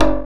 percussion 54.wav